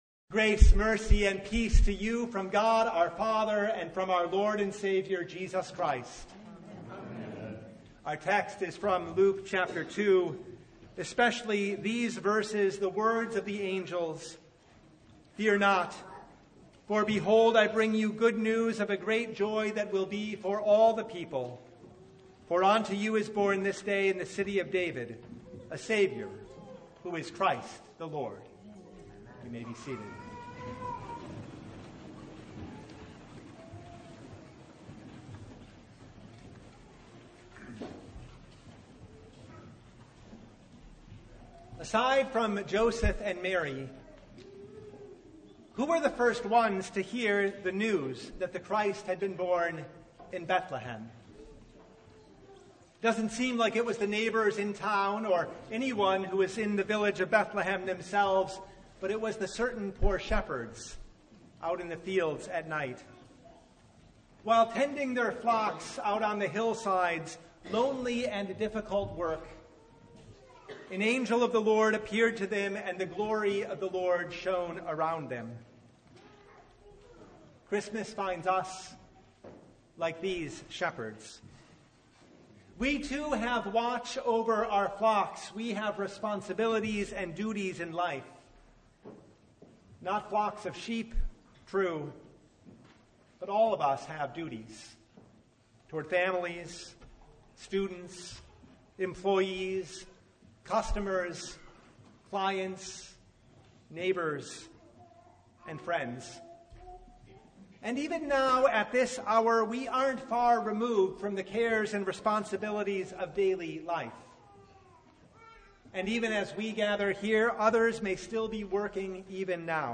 Passage: Luke 2:1-16 Service Type: Christmas Eve Vespers Topics: Sermon Only « Christmas Eve Vespers